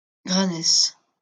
Granès (French pronunciation: [ɡʁanɛs]